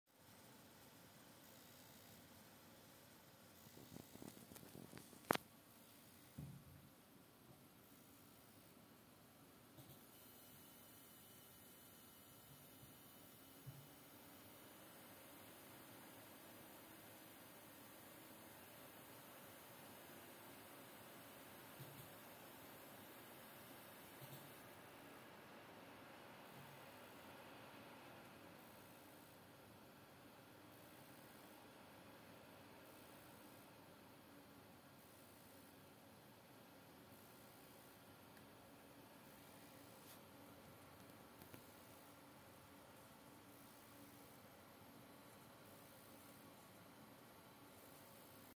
ich habe heute eine neue saphire pulse amd 6700 xt eingebaut. die grafikkarte produziert sobald eine 3D anwendung gestartet wird eine art brumm- oder surrgeräusch. unter wenig last in einem interval, unter voller last konstant. ich habe versucht es mit dem handy aufzunehmen, was halbwegs...
ich habe versucht es mit dem handy aufzunehmen, was halbwegs geklappt hat.
am besten hörbar ist es nachdem die lüfter wieder runter fahren.
man hört dann die lüfter hochdrehen.
hier hört man dann das interval-brummen ganz gut.